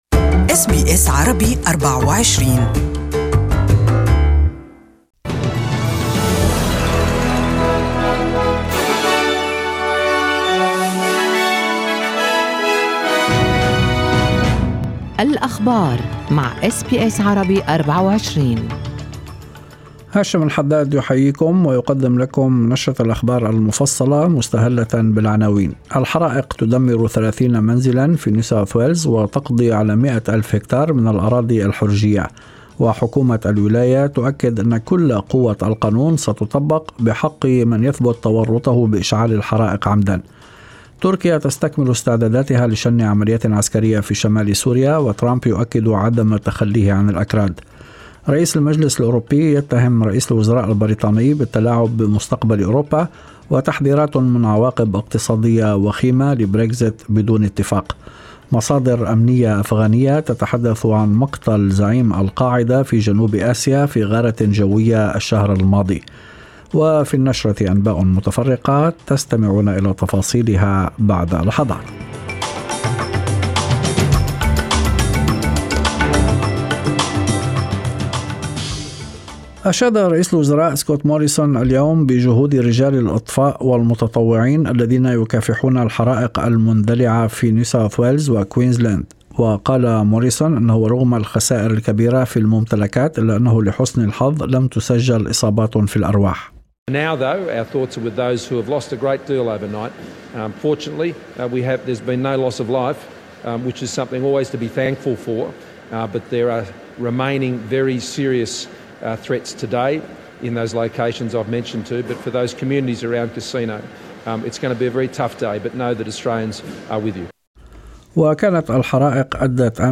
Evening News: Four states affected by bush fire